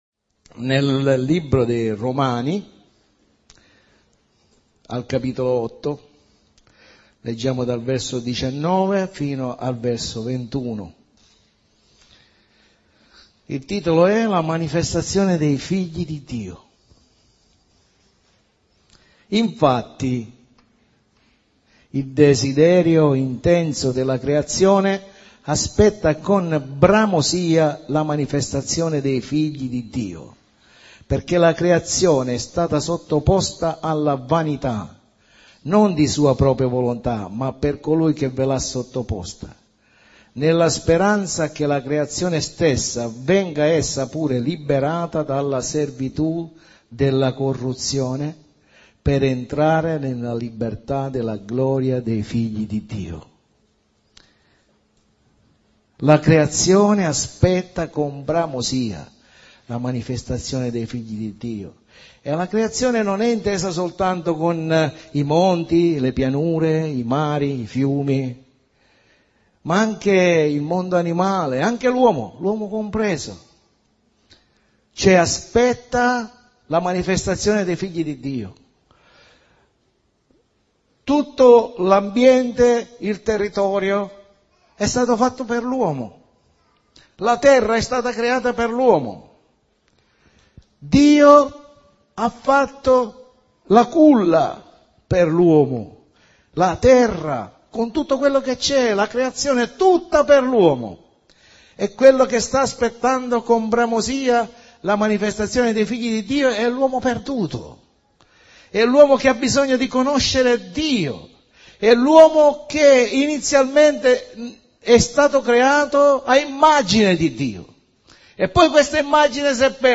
Pred.